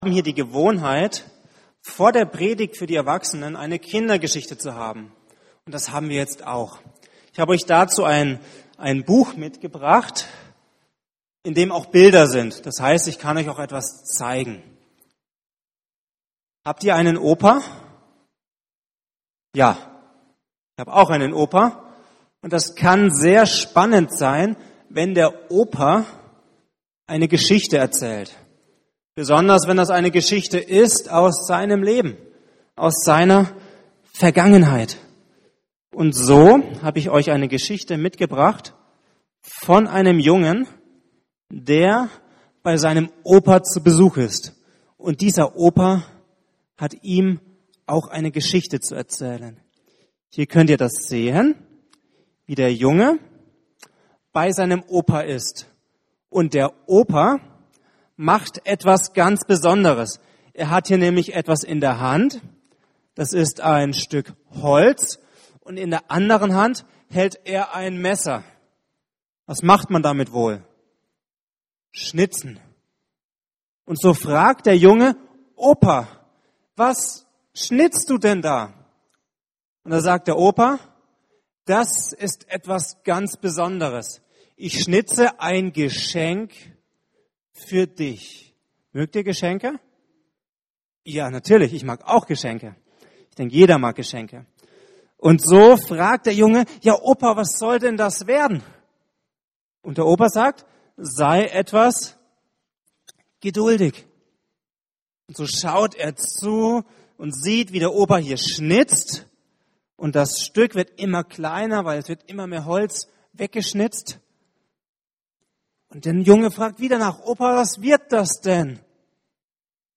Taufgottesdienst
Predigten